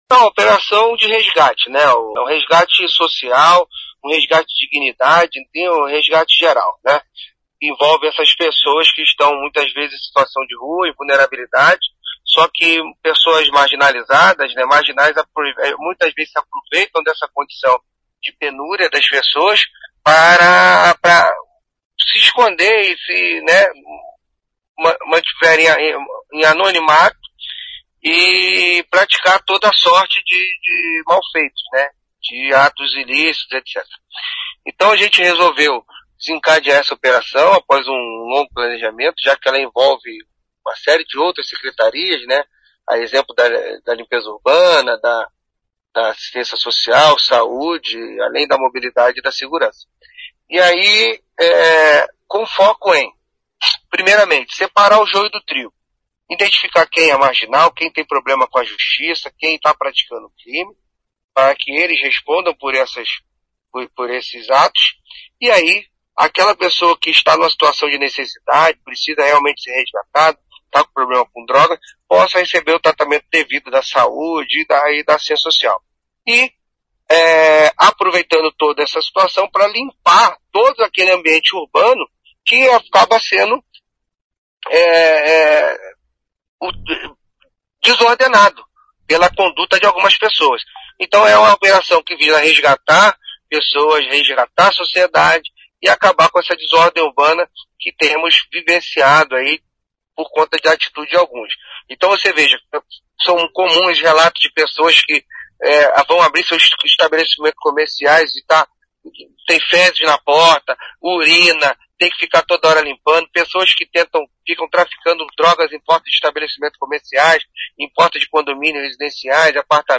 Ouça o que diz o secretário de Segurança, Luiz Alves.